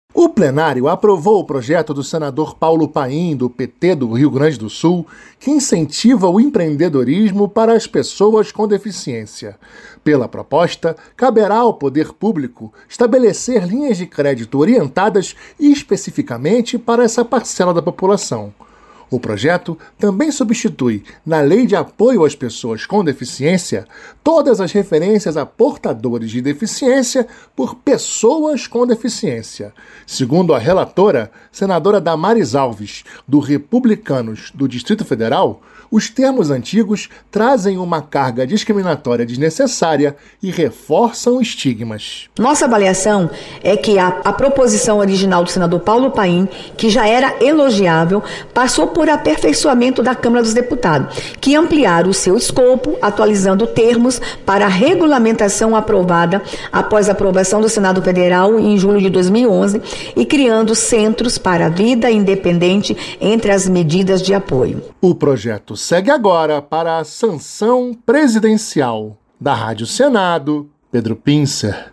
Senadora Damares Alves